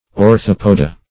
Search Result for " orthopoda" : The Collaborative International Dictionary of English v.0.48: Orthopoda \Or*thop"o*da\, prop. n. pl.